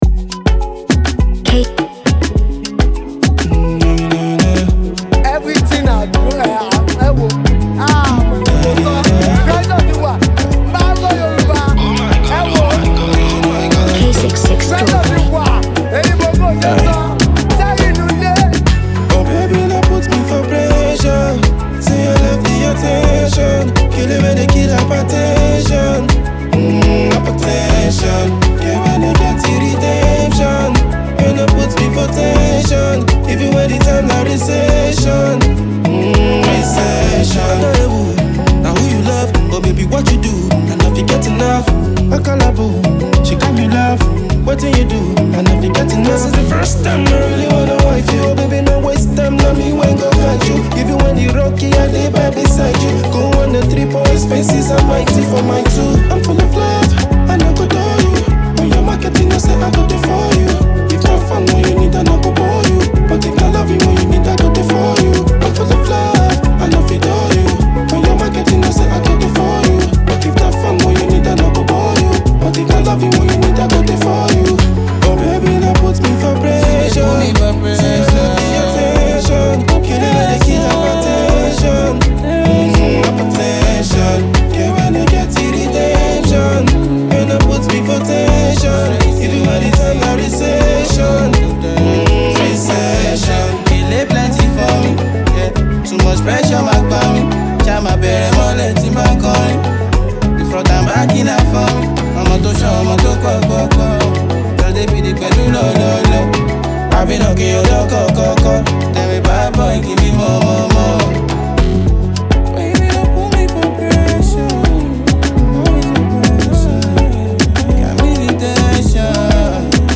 With its well-crafted lyrics and captivating melody